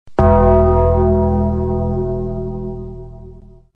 The Undertaker Bell
u3-The-Undertaker-Bell.mp3